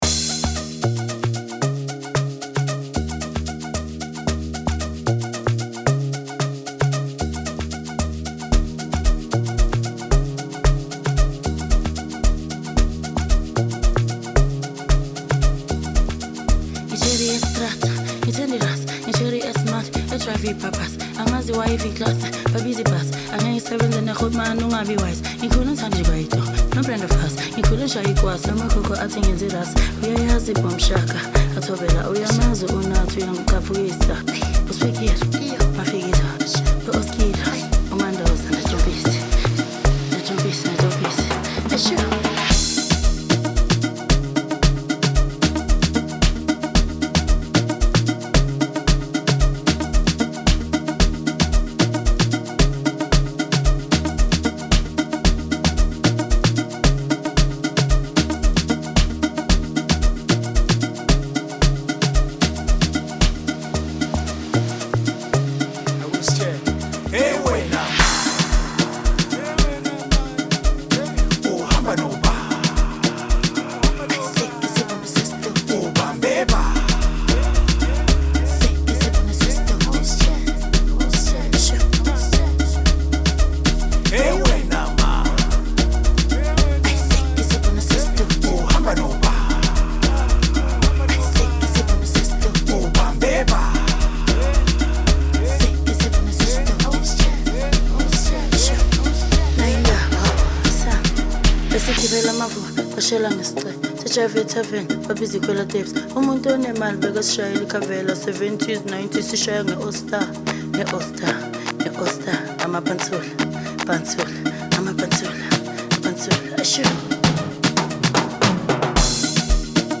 a beautiful House/Amapiano filtered song